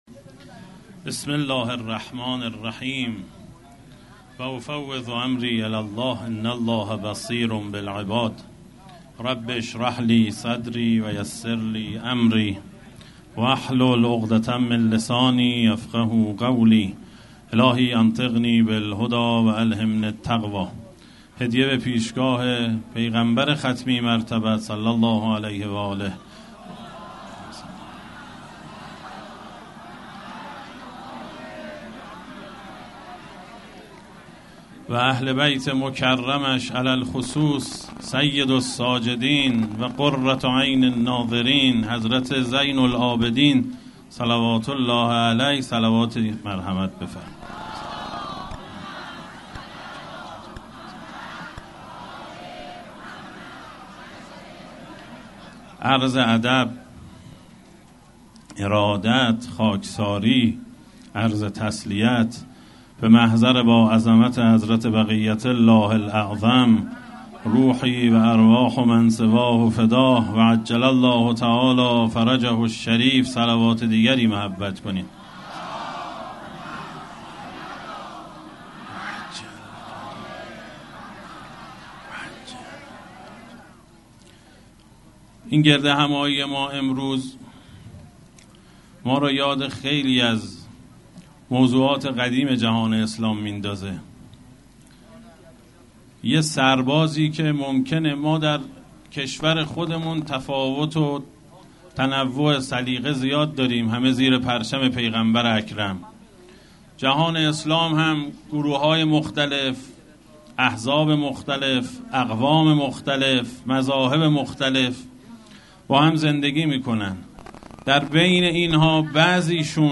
سخنرانی در اجتماع میدان فلسطین به مناسبت شهادت شهید هنیه